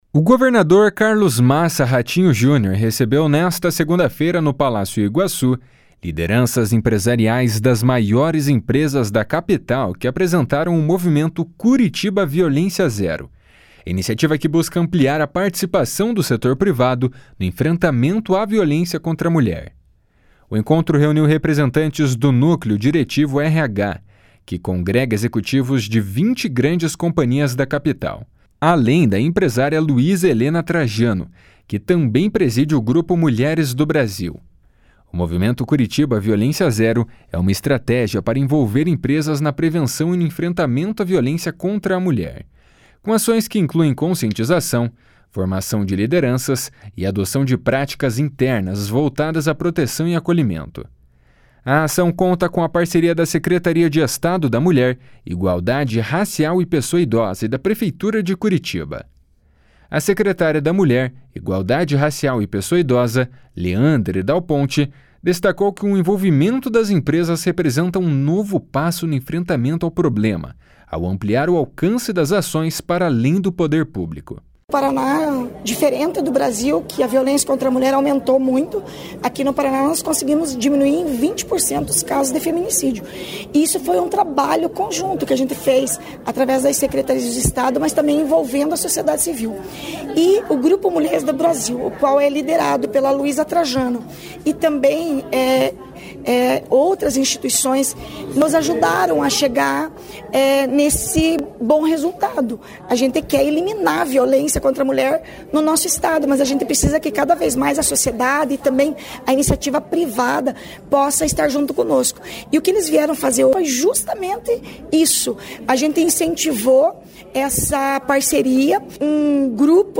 A empresária Luiza Helena Trajano elogiou o trabalho desenvolvido pelo Paraná e reforçou a importância da atuação conjunta entre diferentes setores. // SONORA LUIZA HELENA //